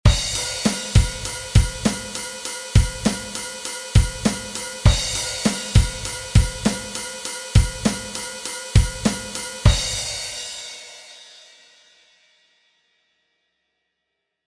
Drum Grooves #3
Und es geht weiter mit einem Achtelrhythmus, der voll durchzieht! Hier sind keine komplexen Techniken notwendig.
drum_grooves_3.mp3